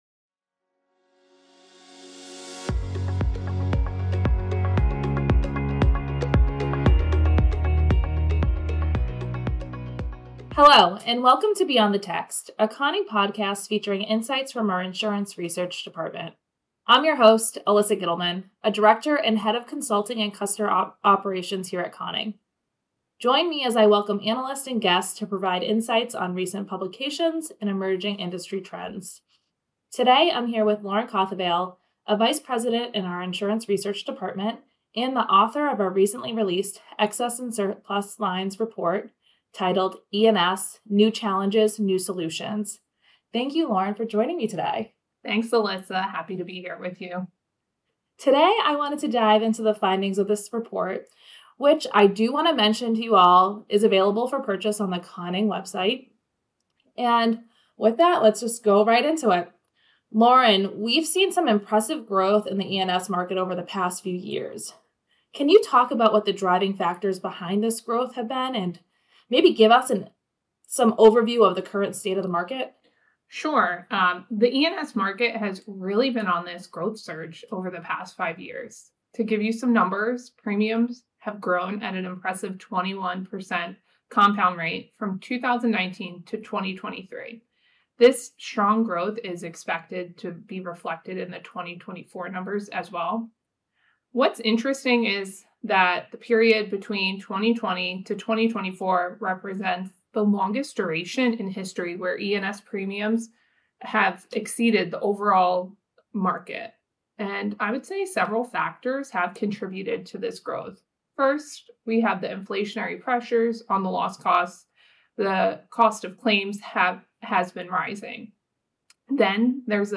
The conversation also dives into how technology and specialization are shaping the future, the challenges and opportunities ahead, and the impact of new entrants on market dynamics.